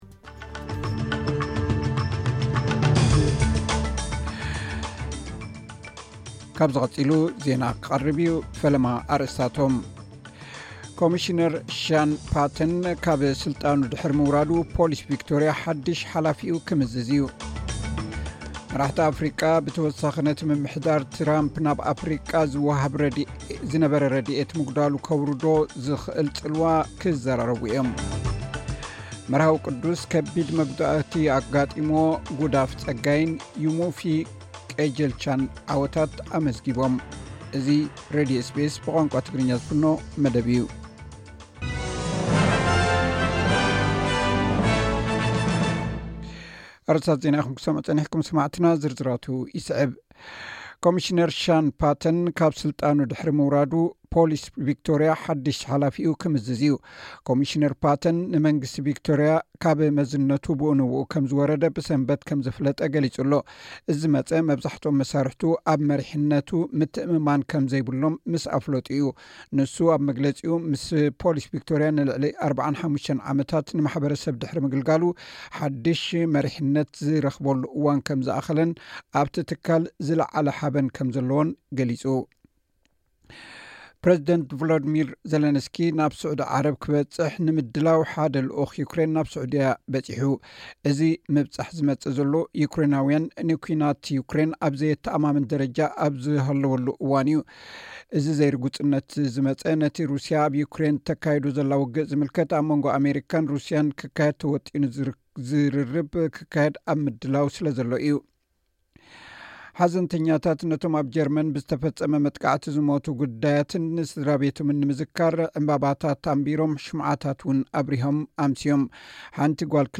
ዕለታዊ ዜና ኤስ ቢ ኤስ ትግርኛ (17 ለካቲት 2025)